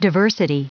Prononciation du mot diversity en anglais (fichier audio)
Prononciation du mot : diversity